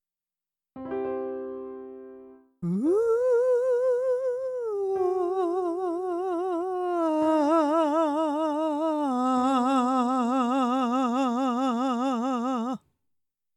くっきり母音を切り替えずに、滑らかに母音をグラデーションさせていく
あくまで裏声の要素を含んだ地声に下降します
音量注意！